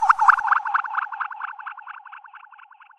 Category 🌿 Nature
ambient animal bird birdsong delay dub echo effect sound effect free sound royalty free Nature